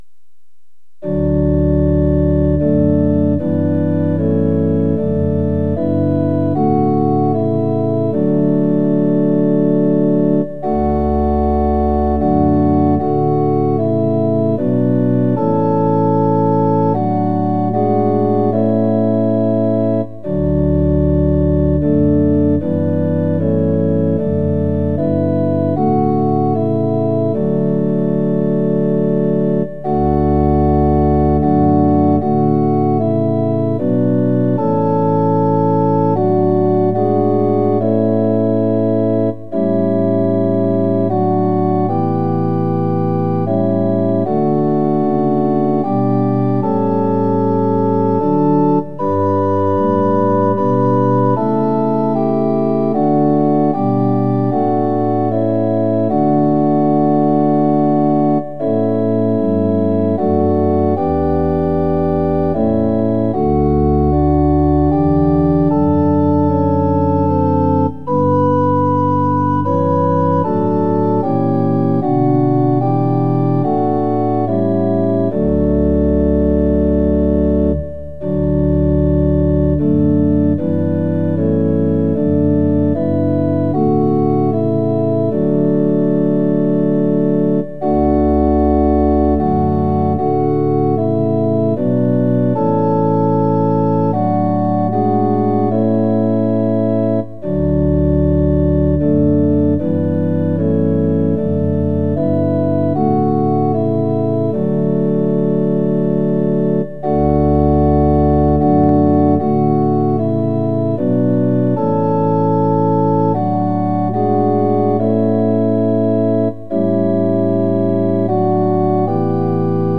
◆４分の３拍子　：　１拍目から始まります。